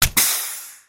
Звуки спускающегося колеса